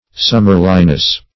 Search Result for " summerliness" : The Collaborative International Dictionary of English v.0.48: Summerliness \Sum"mer*li*ness\, n. The quality or state of being like summer.